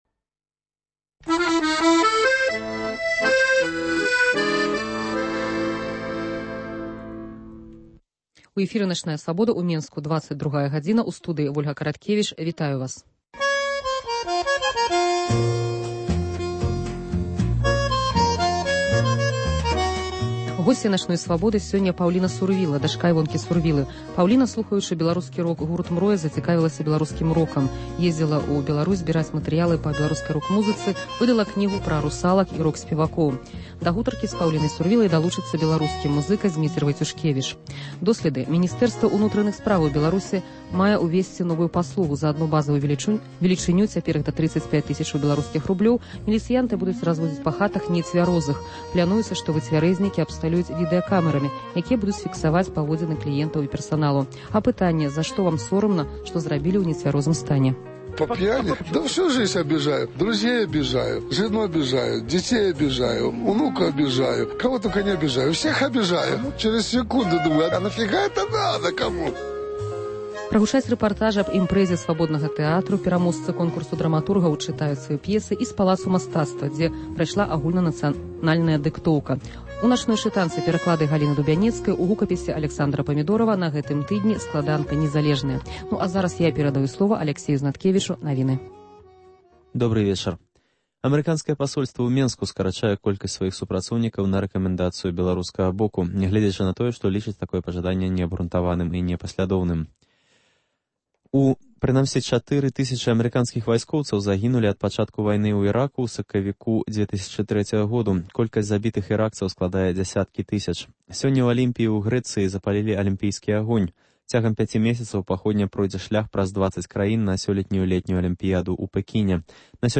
* Пераможцы праводжанага "Свабодным тэатрам" конкурсу драматургаў чытаюць свае п'есы. * У "Палацы мастацтва" прайшла агульнанацыянальная дыктоўка.